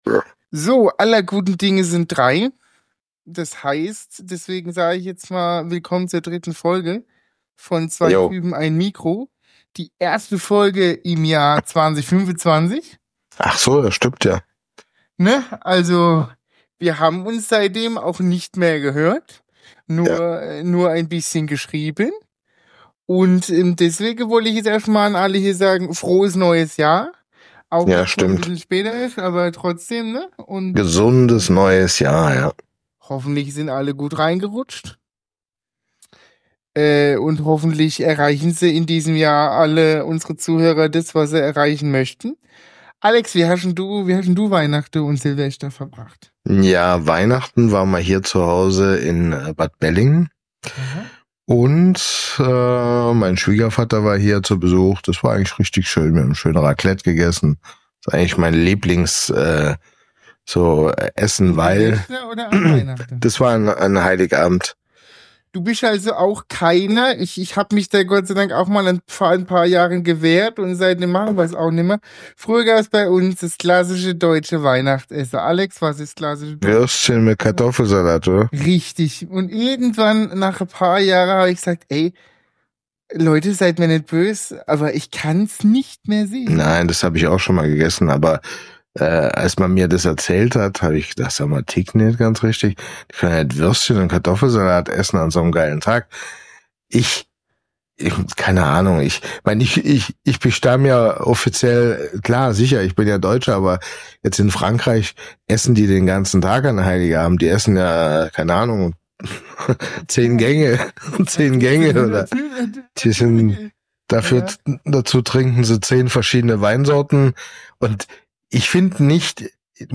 Eine Stunde mit zwei Typen